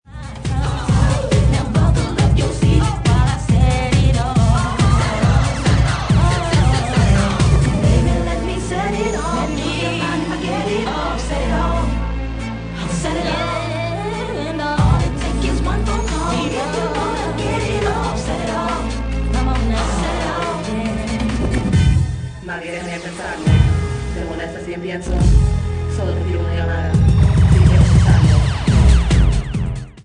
at 138 bpm